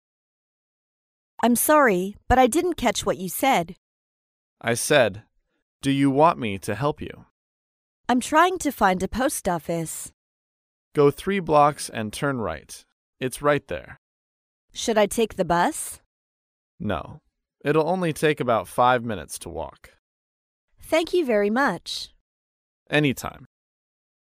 在线英语听力室高频英语口语对话 第27期:帮忙找邮局的听力文件下载,《高频英语口语对话》栏目包含了日常生活中经常使用的英语情景对话，是学习英语口语，能够帮助英语爱好者在听英语对话的过程中，积累英语口语习语知识，提高英语听说水平，并通过栏目中的中英文字幕和音频MP3文件，提高英语语感。